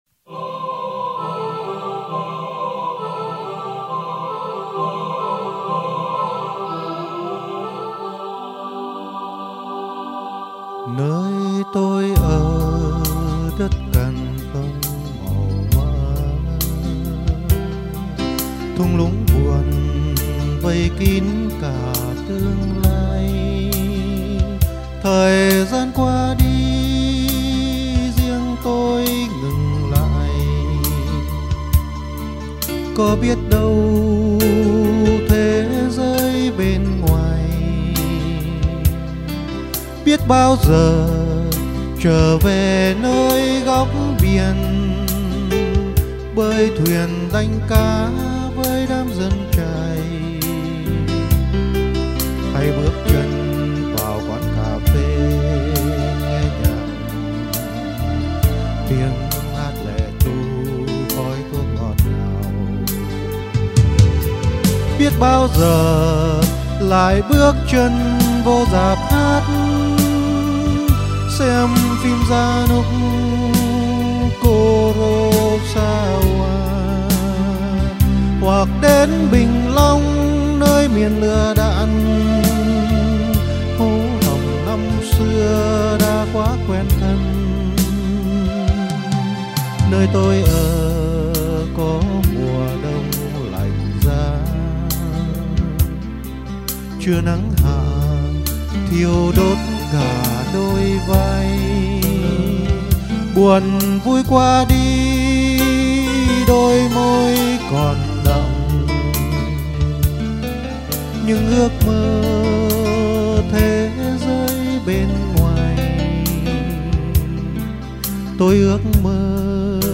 Thú thật ngày đó tôi chưa thấm thía hết cái đẹp của ca từ, mà sự cảm nhận qua âm điệu như vô tình chạm vào vô thức, mặc nhiên giọng hát và tiếng đàn của anh dù là hát cho riêng anh nhưng điều anh không ngờ là tác dụng của nó phần nào giúp cho các bạn đồng tù xua tan đi những cơn ác mộng trong giấc ngủ hằng đêm.